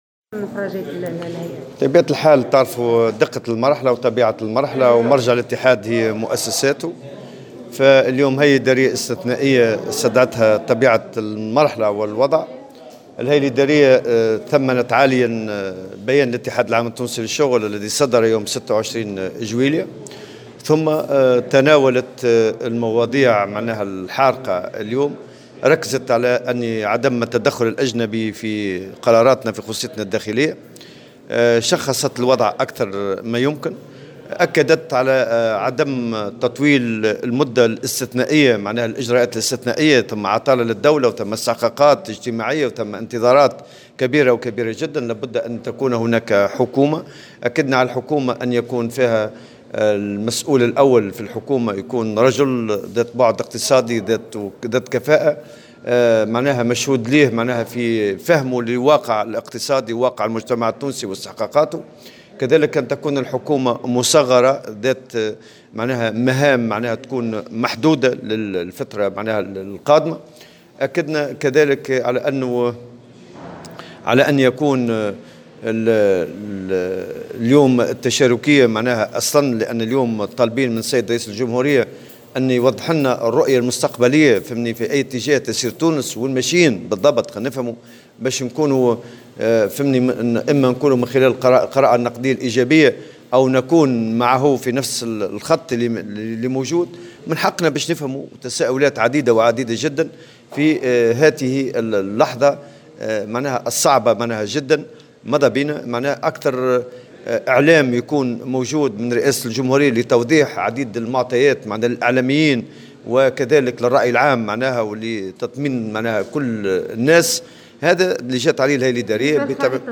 وشدد الطبوبي في تصريح لمراسلة الجوهرة أف أم، عقب انعقاد الهيئة الادارية الاستثنائية للاتحاد في الحمامات، على ضرورة أن يوضح رئيس الجمهورية الرؤية بخصوص تفاصيل خارطة الطريق التي سيتم اعتمادها في الفترة القادمة، معتبرا أن المنظومة القديمة قد انتهت وأن البلاد هي اليوم في حاجة إلى مراجعات وإصلاحات.